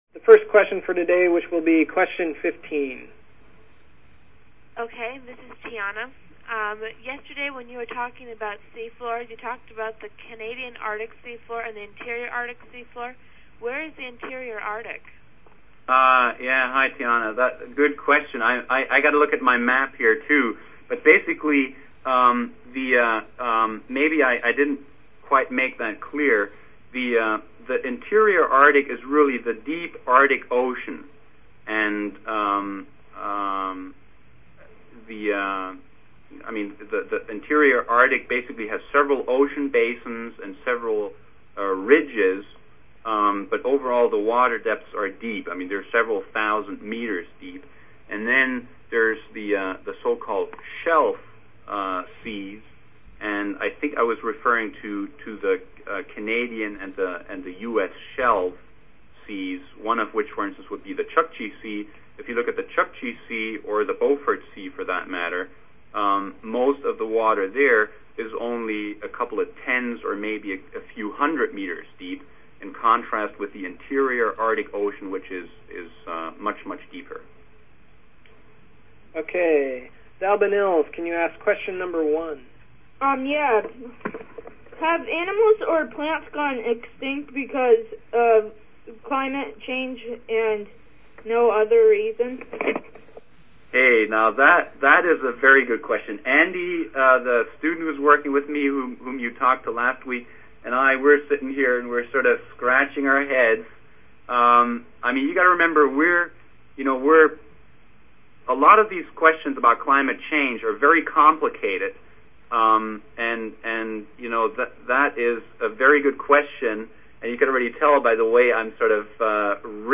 Day 5 Summary - Audio Conference